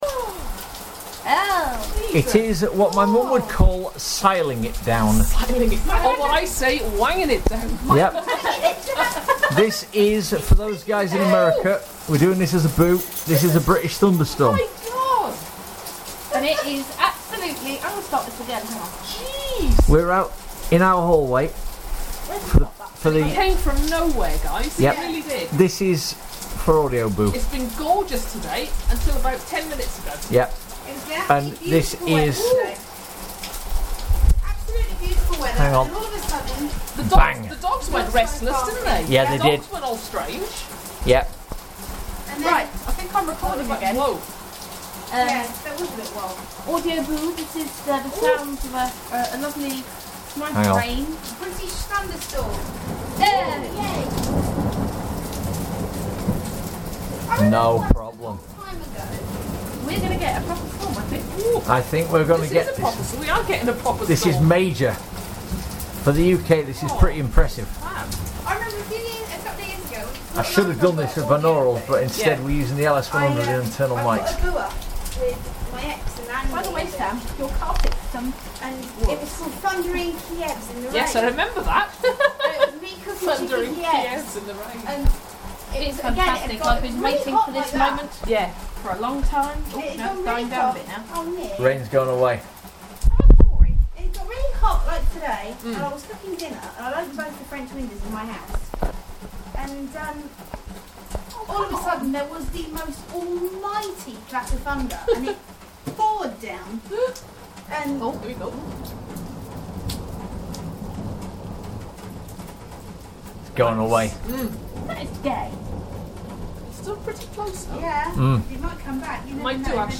a nice bit of thunderstorm action!
well guys, I decided to try the olympus LS100 out on a thunderstorm, this wasn't a huge storm, but lets see what you think e?